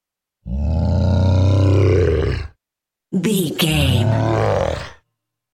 Monster growl medium creature x2
Sound Effects
scary
ominous
eerie